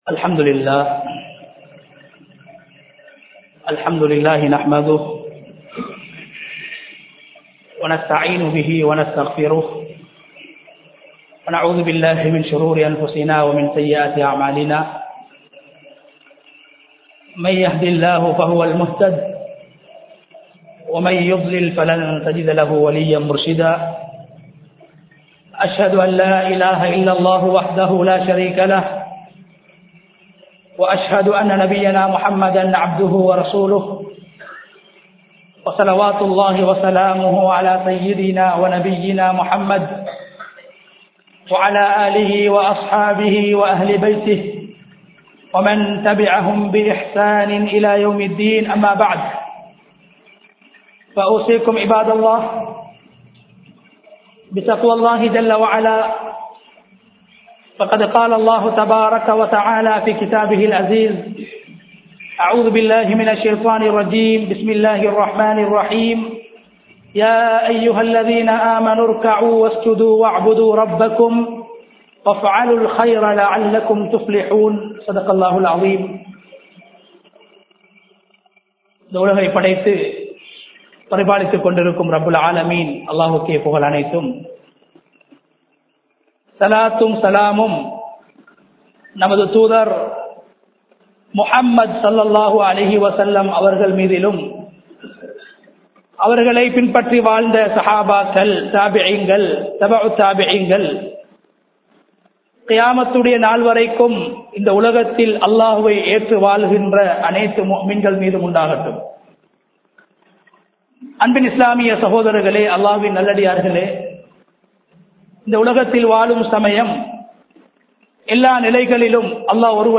நபி(ஸல்) அவர்கள் அல்லாஹ்விடம் கேட்ட கேள்விகள் | Audio Bayans | All Ceylon Muslim Youth Community | Addalaichenai
Mattakulliya Bilal Jumuah Masjith